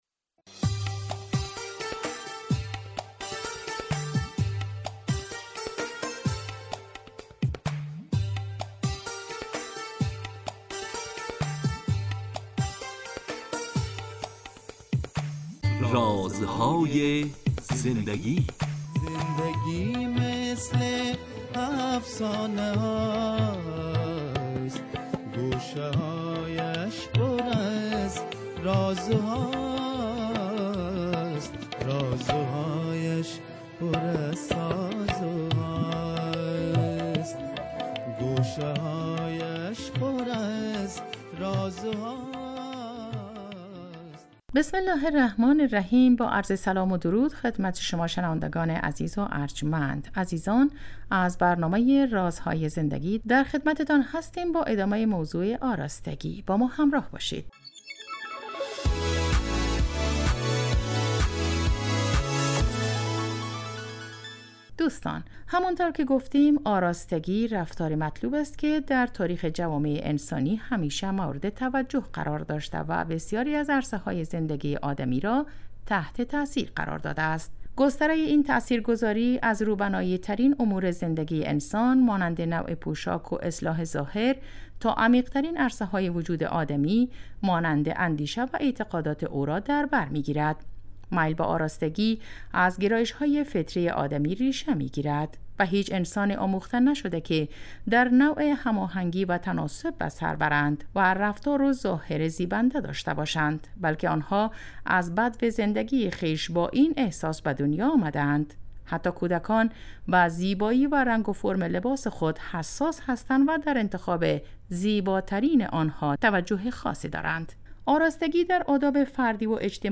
این برنامه به مدت 15 دقیقه هر روز ساعت 11:35 به وقت افغانستان از رادیو دری پخش می شود .